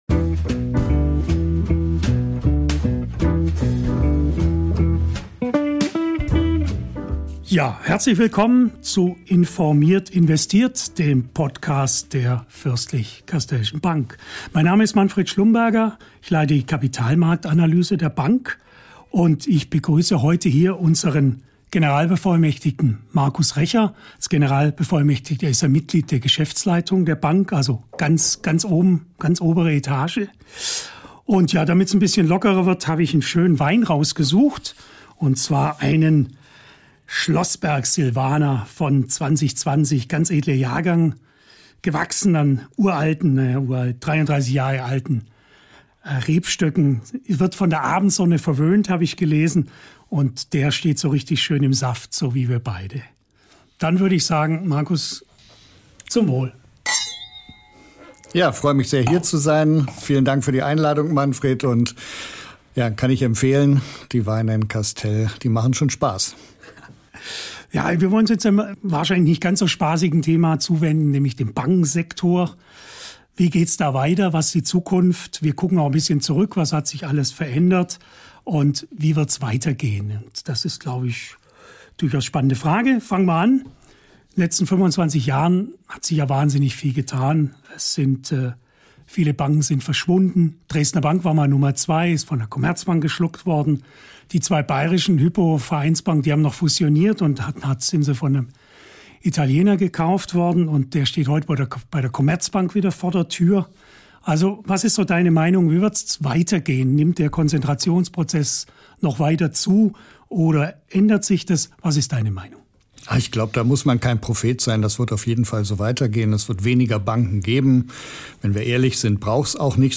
Ehrlich, humorvoll und diesmal bei einem Glas Silvaner!